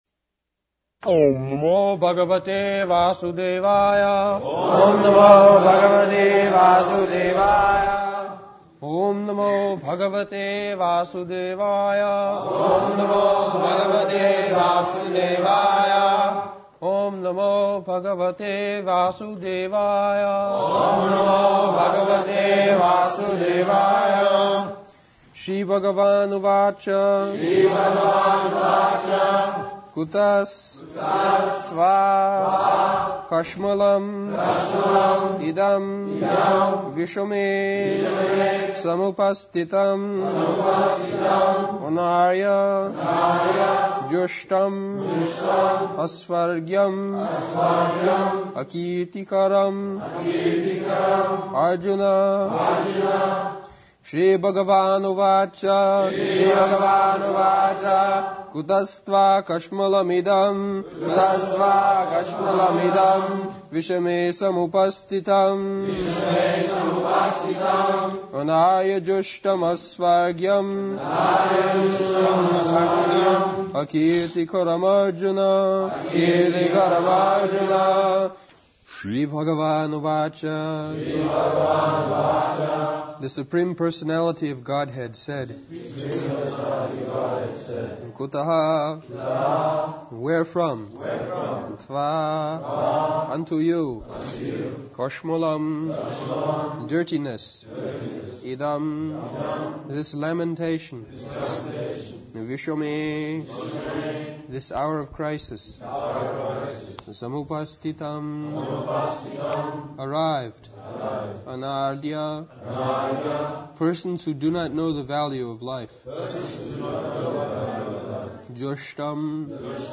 Srila Prabhupada’s Class on Bhagavad-gita 2.2, August 3, 1973, London
(leads chanting of verse) (Prabhupāda and devotees repeat)